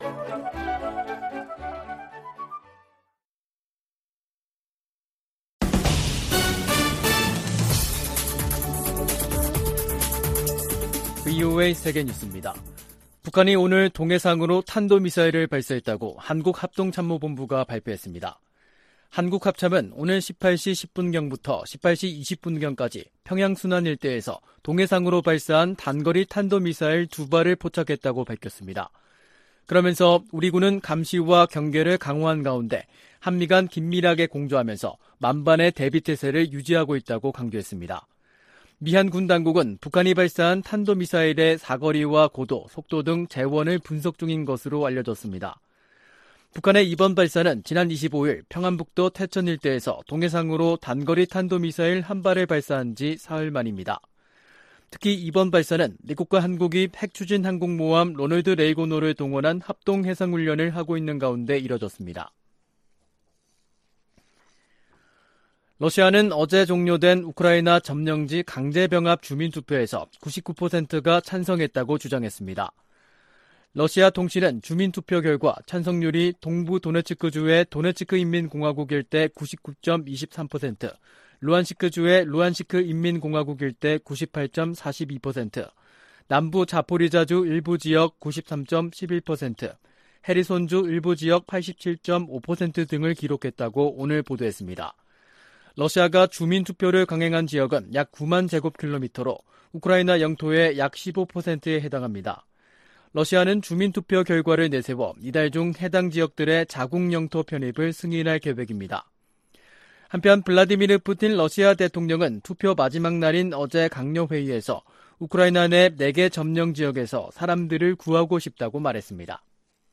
VOA 한국어 간판 뉴스 프로그램 '뉴스 투데이', 2022년 9월 28일 2부 방송입니다. 북한이 동해상으로 미상의 탄도미사일을 발사했다고 한국 합동참모본부가 밝혔습니다. 카멀라 해리스 부통령이 도쿄에서 한국 국무총리와 만나 북핵위협 해결을 위한 협력을 약속했습니다. 해리스 부통령이 한반도 비무장지대를 방문하는 건 방위 공약을 최고위급에서 재확인하는 것이라고 미국 전문가들이 진단했습니다.